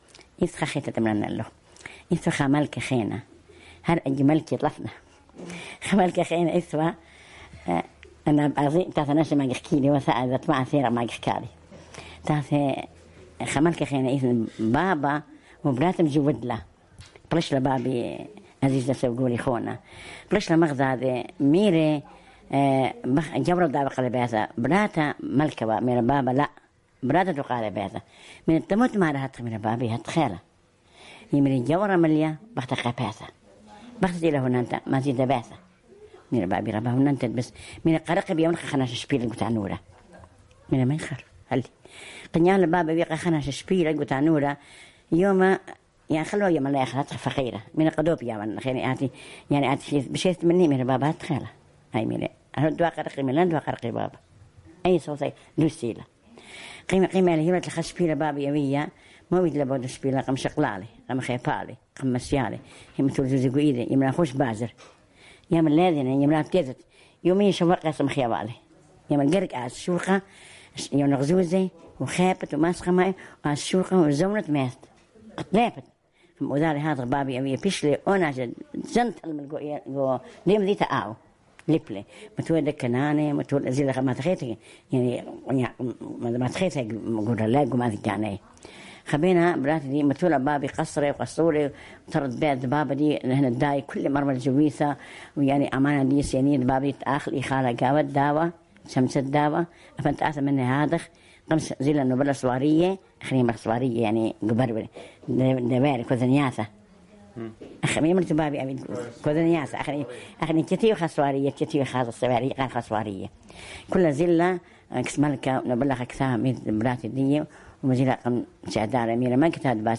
Barwar: The Wise Daughter of the King